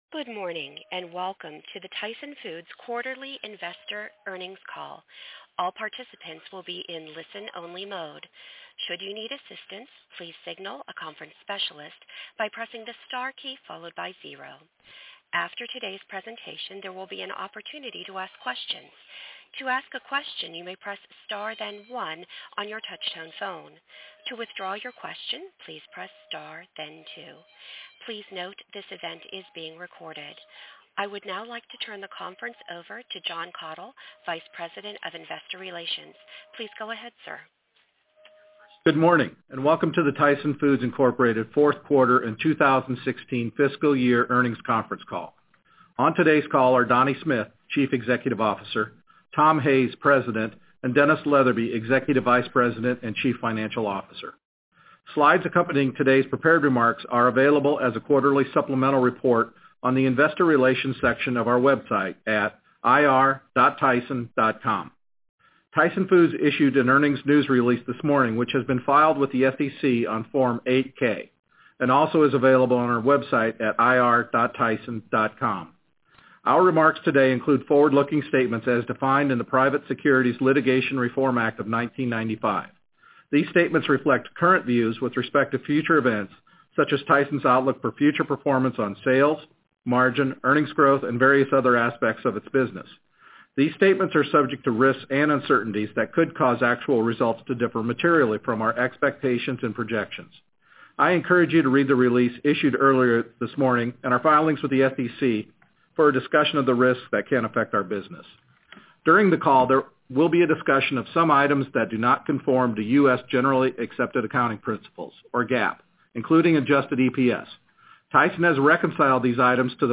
Tyson Foods Inc. - Q4 2016 Tyson Foods Earnings Conference Call